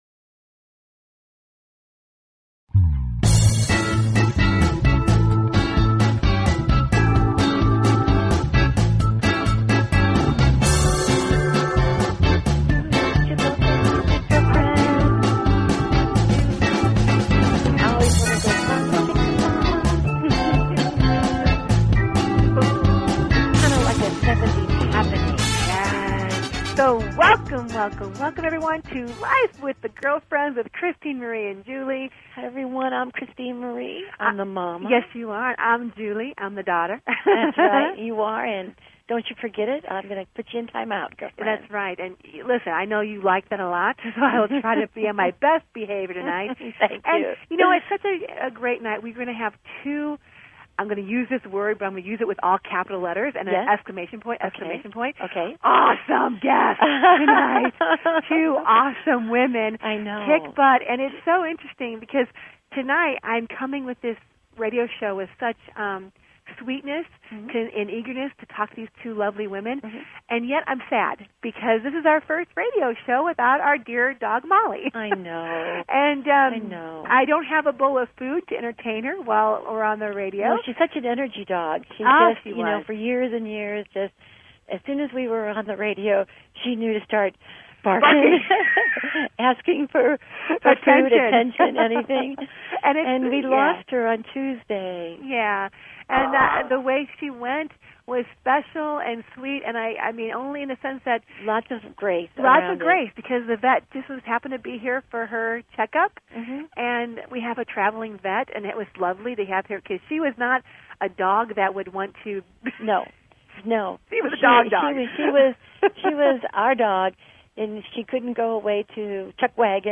Talk Show Episode, Audio Podcast, Life_With_The_Girlfriends and Courtesy of BBS Radio on , show guests , about , categorized as